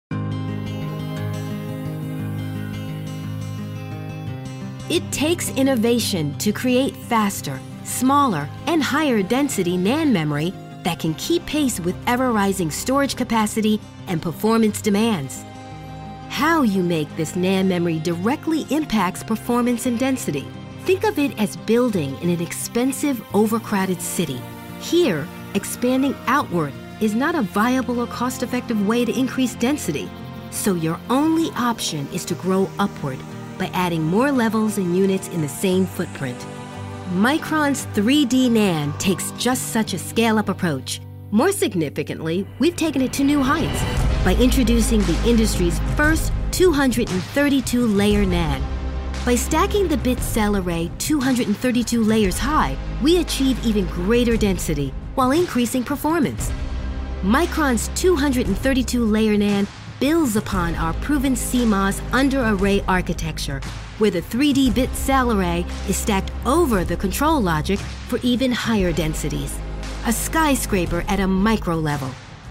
Friendly, Smart and Engaging.
Standard American, New York (Long Island)
Young Adult
Middle Aged